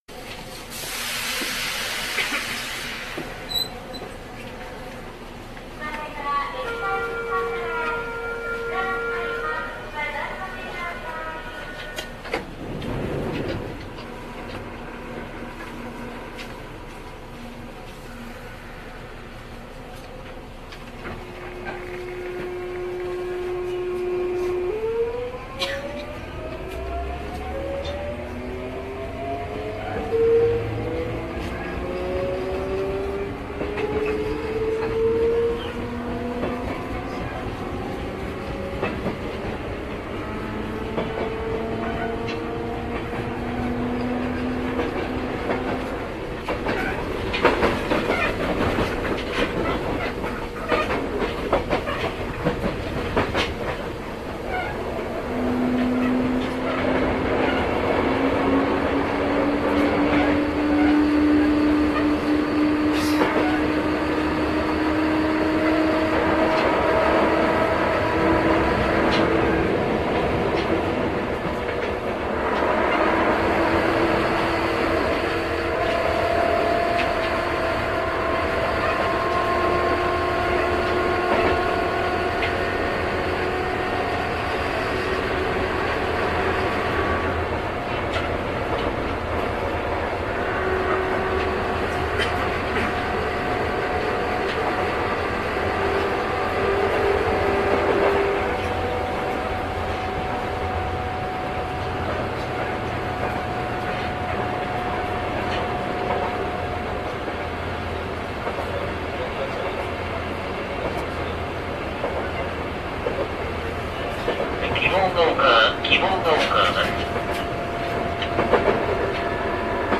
若干8000系にも似ているようですが、音のこもり具合といい、のんびりとした感じといい、やはり独特です。減速時の変調も1回多いですし、冒頭のダイナミックなブレーキ音も実にすばらしい・・・。
かなり苦しそうに聞こえますが空転のせいです。
相鉄本線　二俣川〜希望ヶ丘（5153）